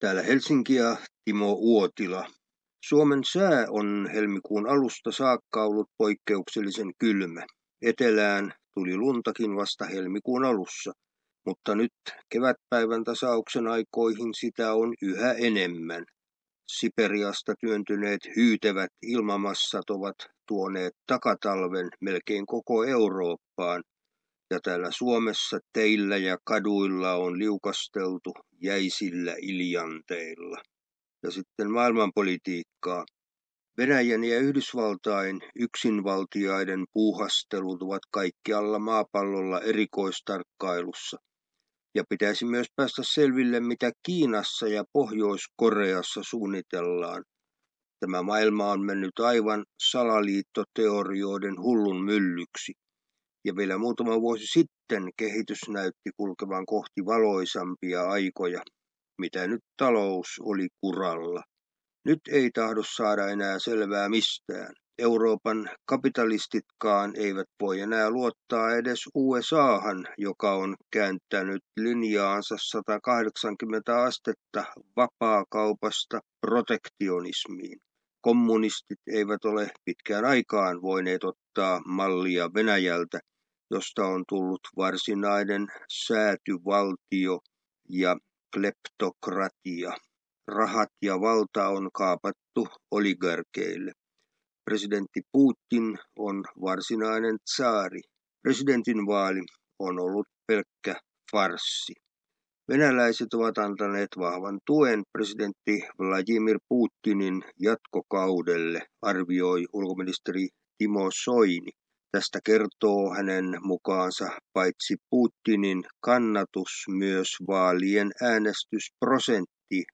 ajankohtaisraportissa